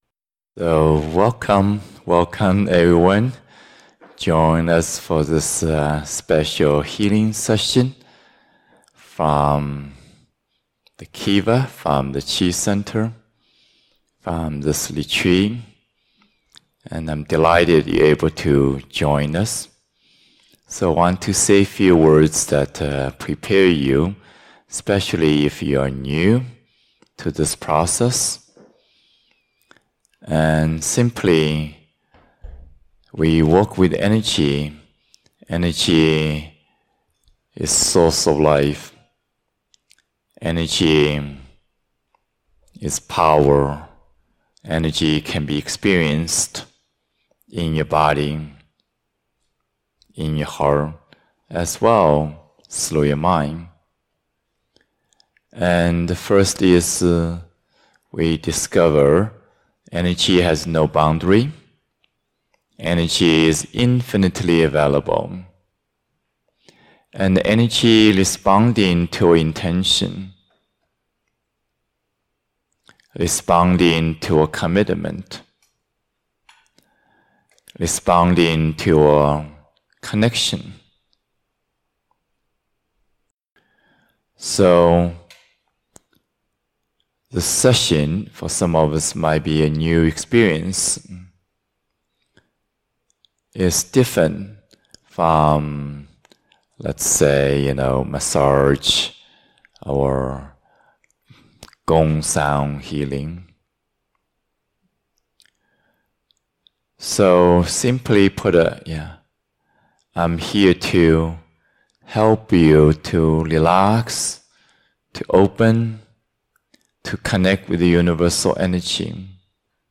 2023-10-04 Healing Session